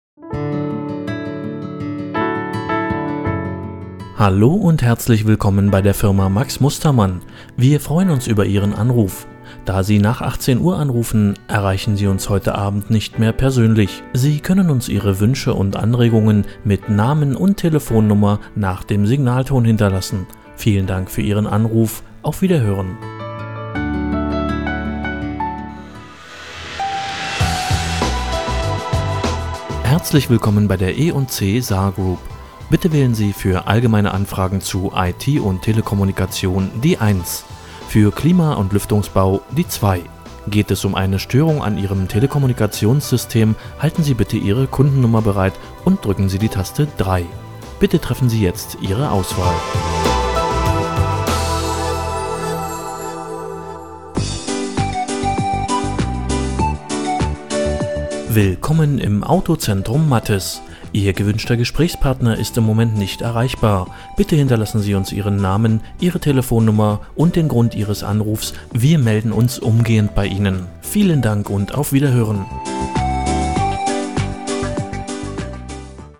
Das sind Werbespots, Hörbücher, Vertonungen und Telefonansagen, die ich im eigenen Studio produziere.
Telefon-Ansagen: